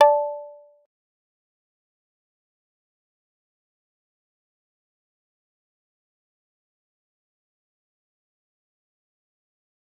G_Kalimba-D5-pp.wav